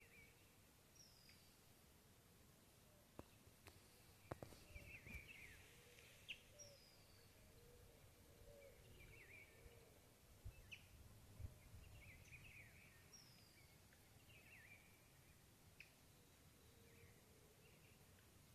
Plain Inezia (Inezia inornata)
Life Stage: Adult
Detailed location: Reserva Pozo Del Arbolito
Condition: Wild
Certainty: Recorded vocal
z_piojito-picudo.mp3